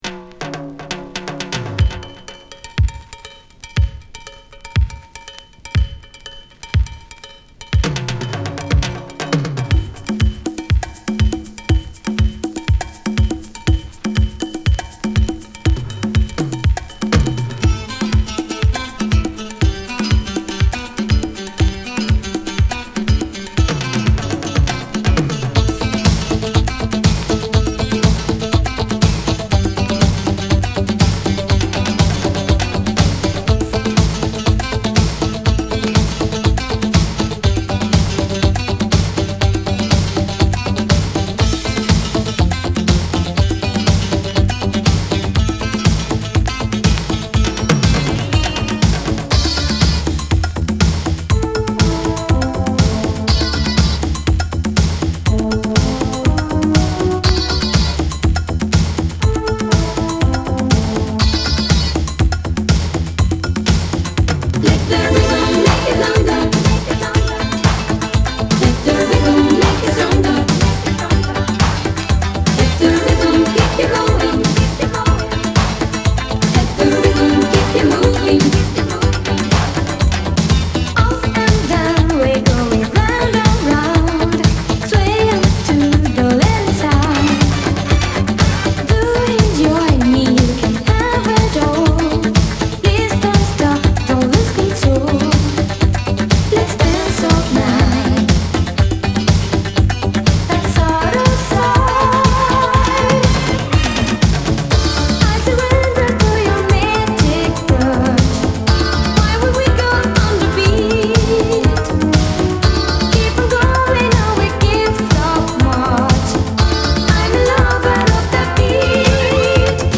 -- Austrian Disco!!